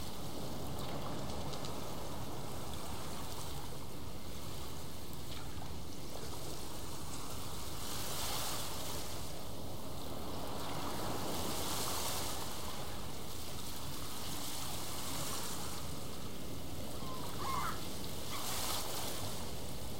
Ambiance A bord d’un voilier (Broadcast) – Le Studio JeeeP Prod
Bruits d’ambiance à bord d’un voilier.
A-bord-dun-voilier.mp3